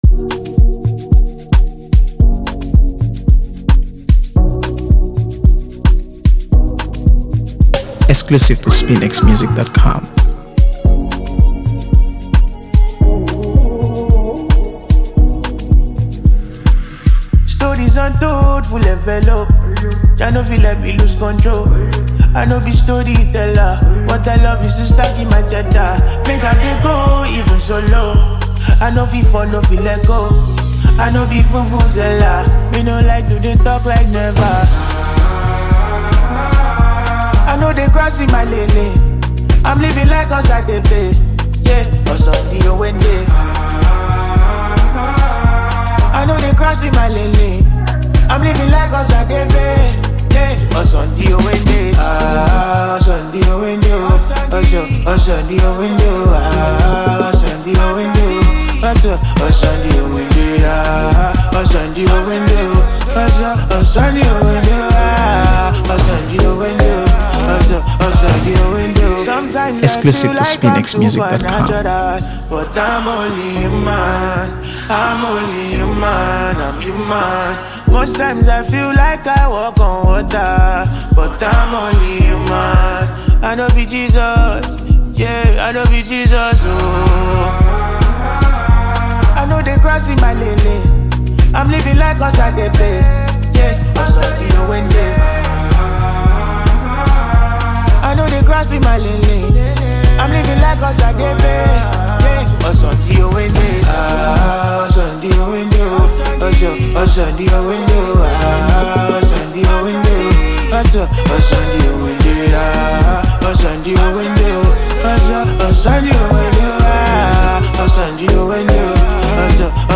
AfroBeats | AfroBeats songs
uplifting, soulful, and instantly memorable
the song features lush instrumentals